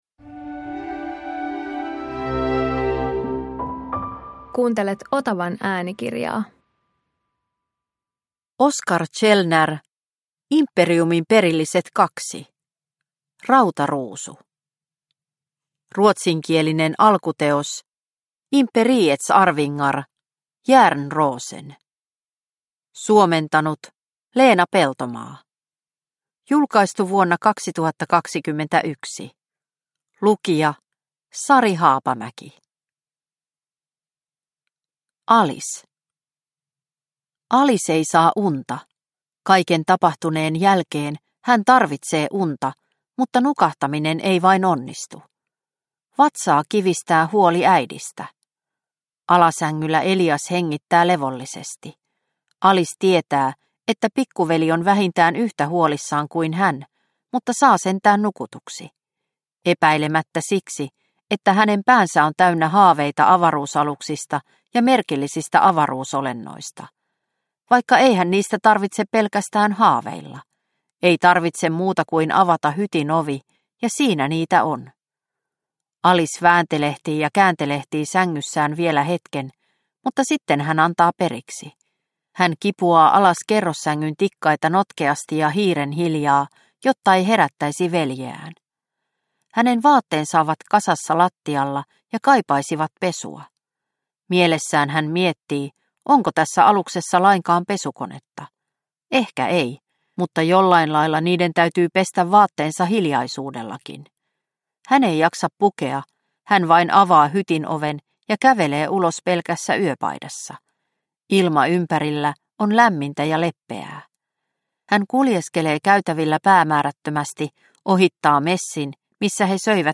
Imperiumin perilliset 2 Rautaruusu – Ljudbok – Laddas ner